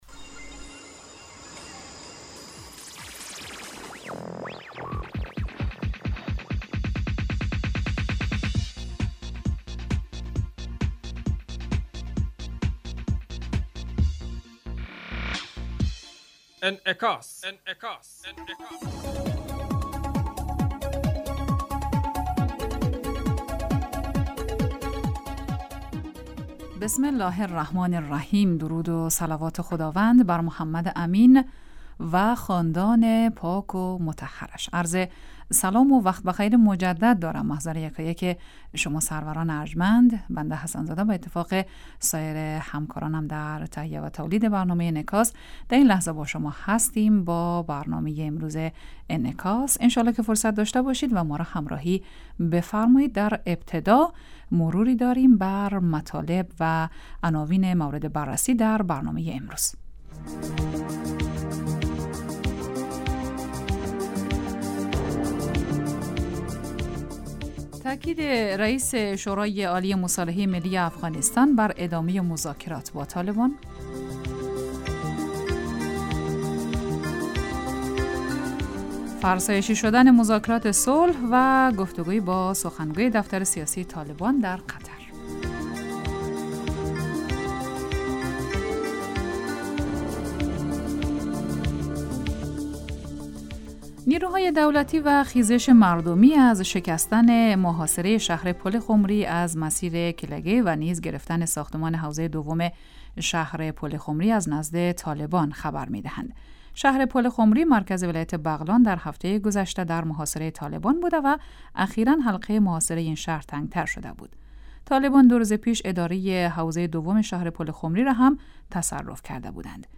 تاکید رئیس شورای عالی مصالحه ملی افغانستان بر ادامه مذاکرات با طالبان. فرسایشی شدن مذاکرات صلح و گفت و گو با سخنگوی دفتر سیاسی طالبان در قطر.
برنامه انعکاس به مدت 30 دقیقه هر روز در ساعت 12:10 ظهر (به وقت افغانستان) بصورت زنده پخش می شود. این برنامه به انعکاس رویدادهای سیاسی، فرهنگی، اقتصادی و اجتماعی مربوط به افغانستان و تحلیل این رویدادها می پردازد.